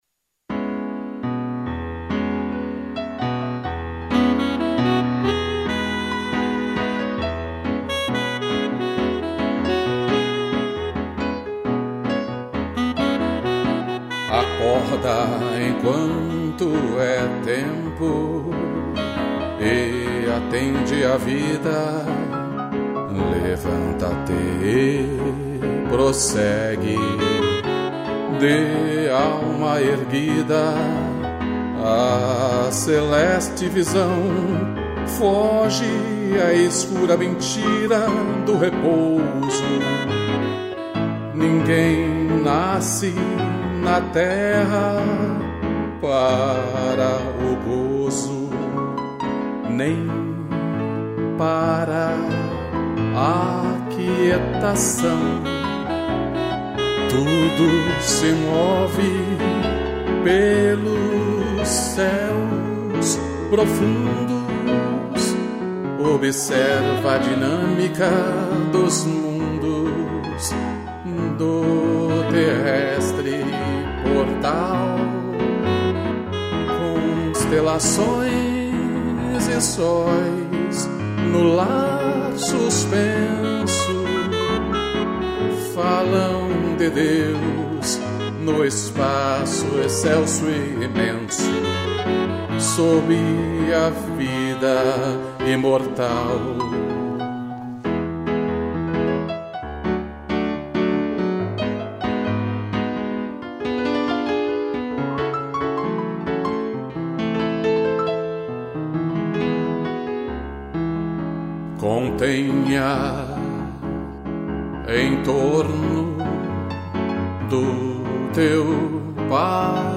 2 pianos e sax